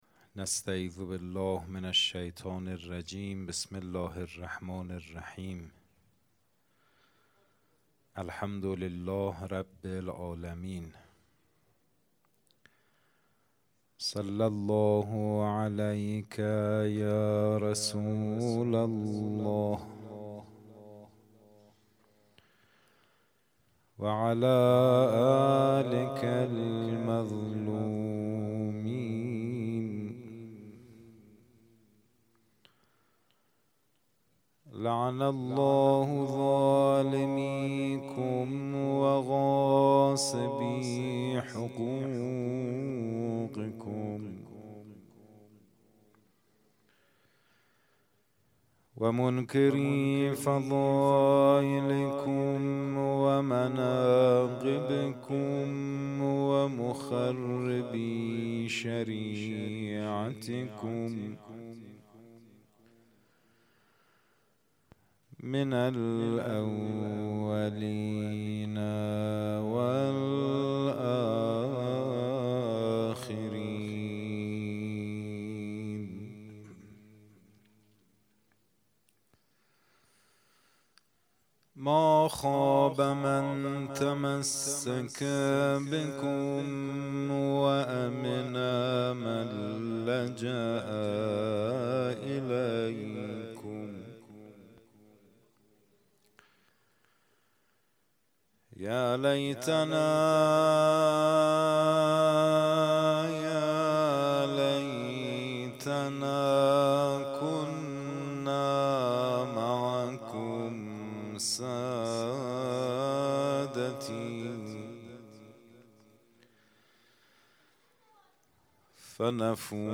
خیمه گاه - روضةالشهداء - سخنرانی